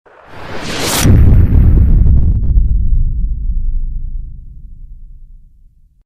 Boom Sound Effect - Botón de Efecto Sonoro